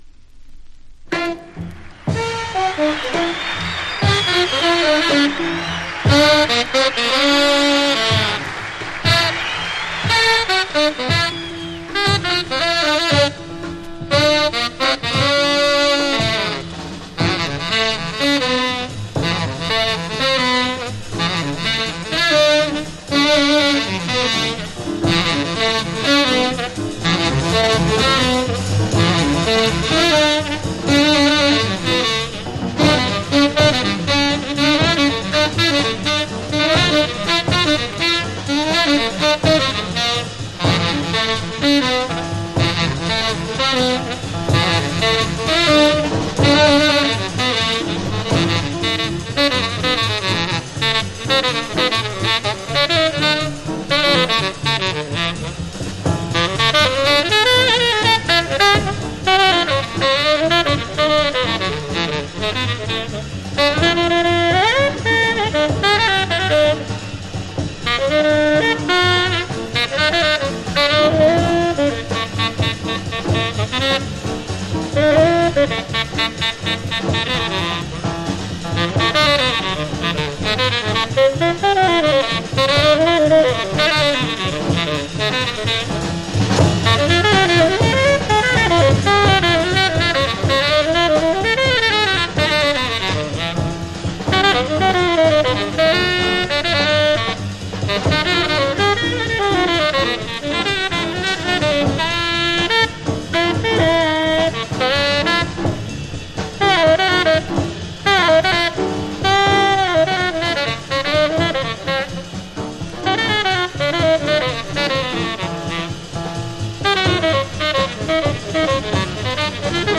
（MONO針で聴くとほとんどノイズでません）
Genre US JAZZ